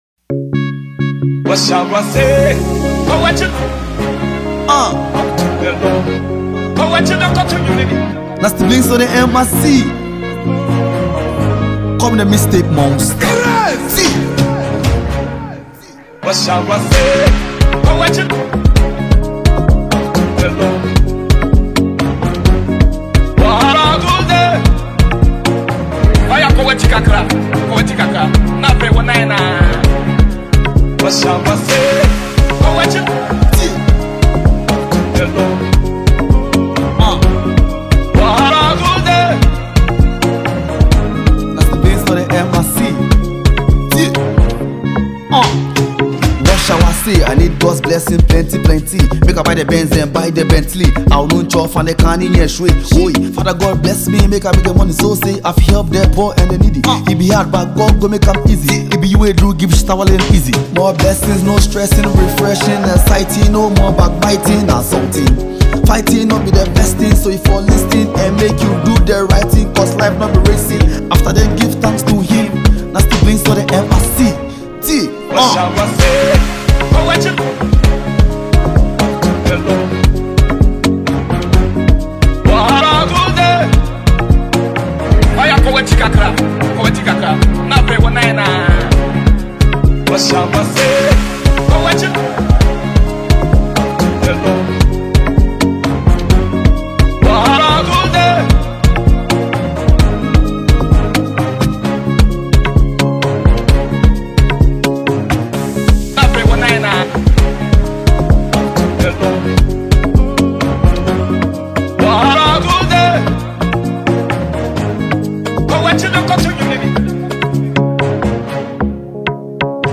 catchy sound